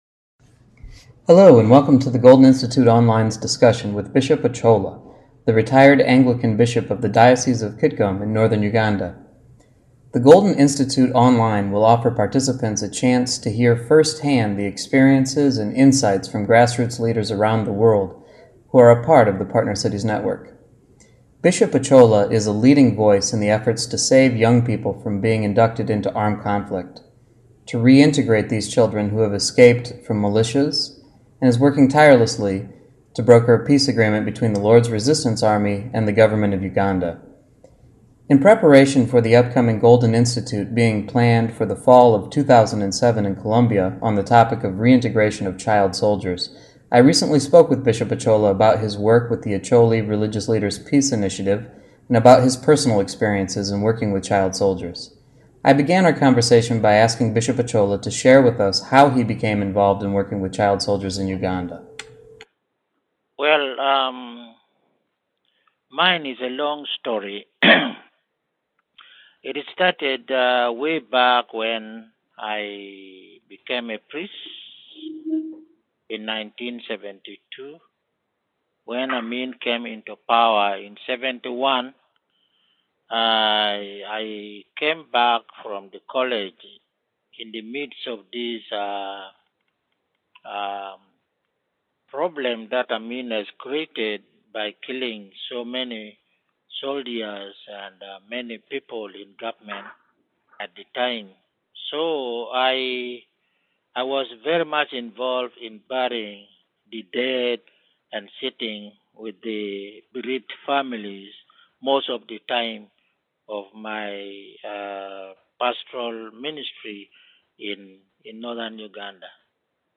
OcholaInterview.mp3